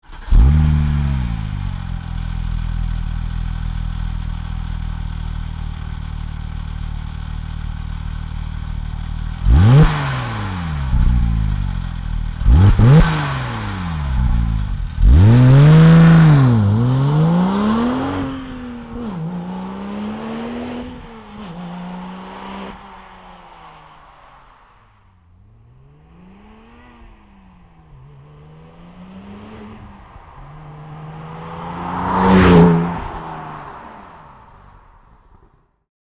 gnb-motorensounds-03-996.mp3